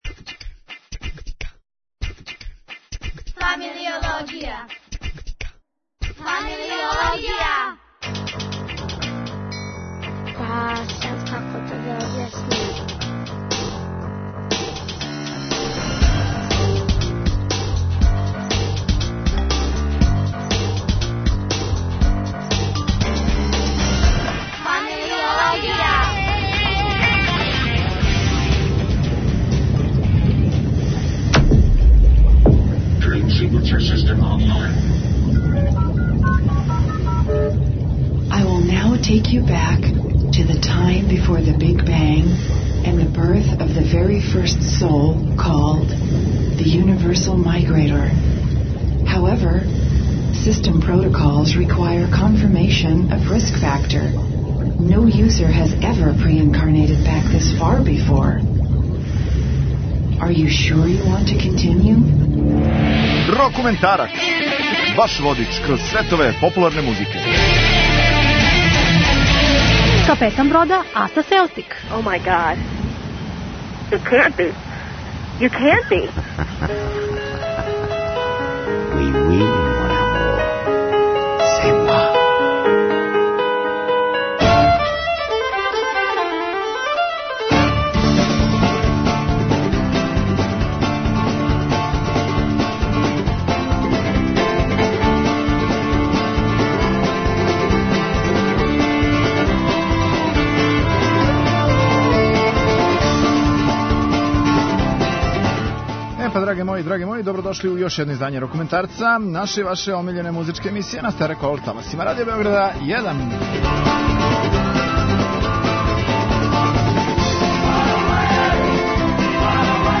У овом 'Рокументарцу' ћете слушати следеће нумере: